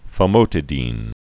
(fə-mōtĭ-dēn)